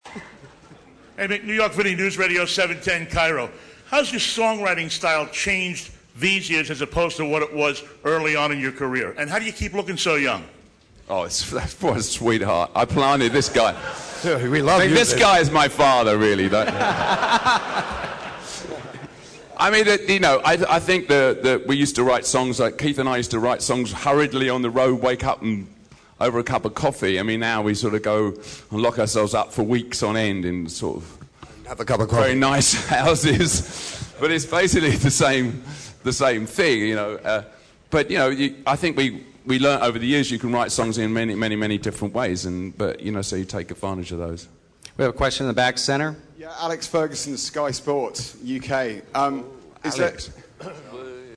you gotta have something goin' on if the rolling stones mick jagger stands up in a press conference and calls you his father but thats exactly what happened at the Super Bowl XL press conference.